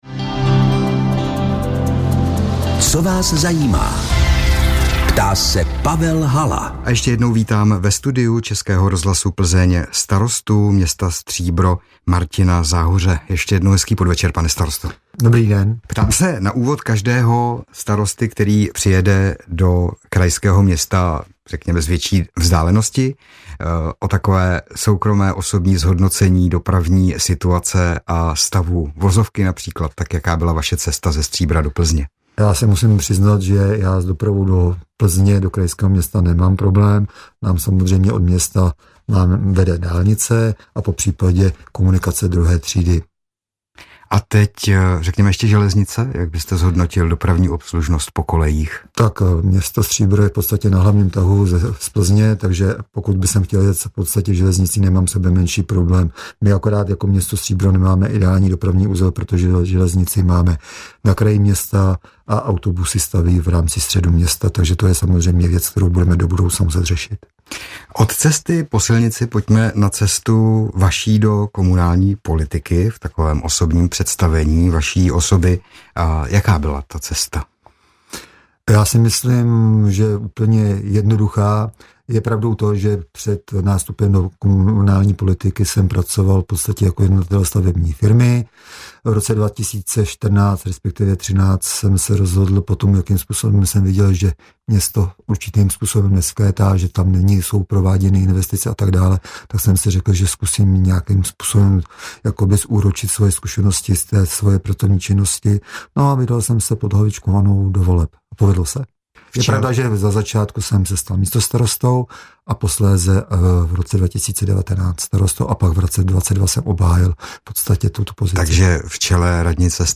Starosta Stříbra na Tachovsku Martin Záhoř (ANO) upozorňuje na problém, který stále častěji trápí obyvatele města.